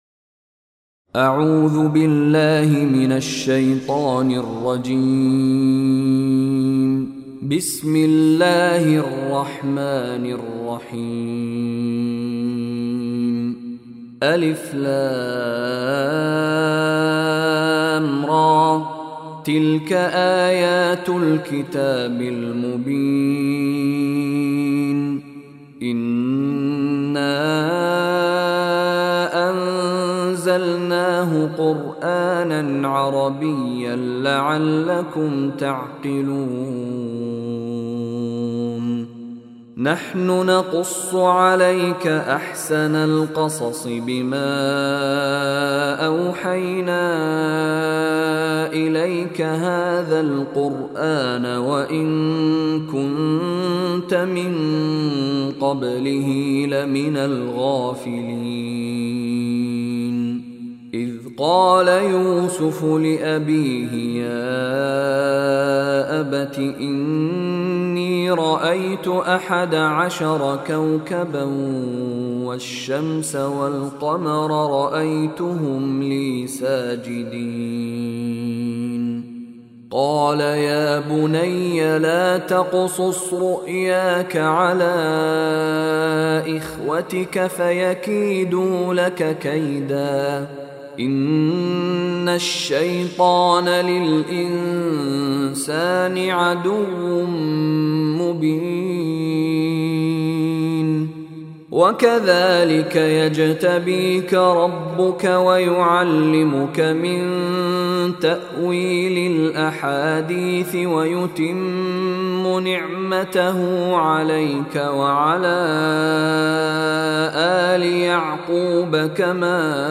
Surah Yusuf Beautiful Recitation MP3 Download By Sheikh Mishary Rashid in best audio quality.